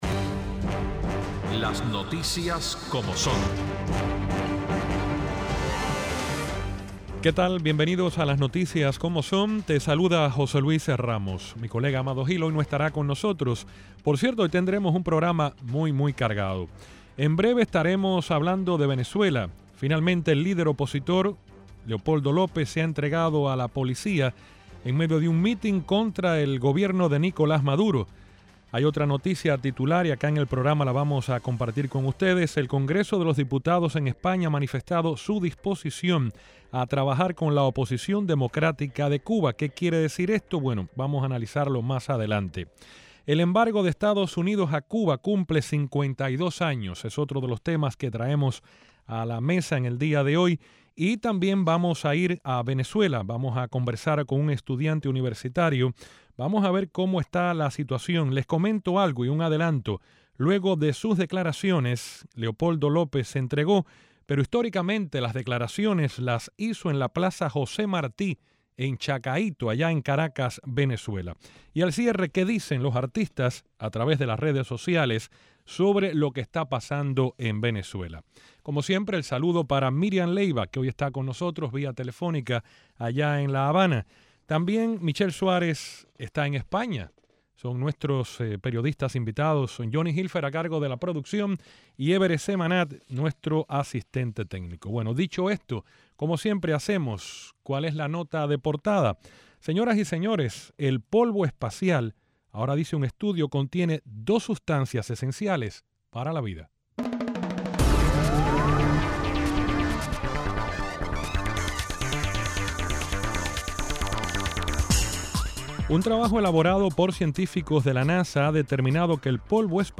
desde Madrid, comentan las noticias más importantes del día